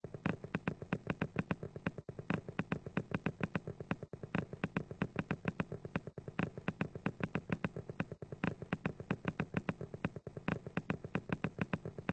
footsteps_race_less.ogg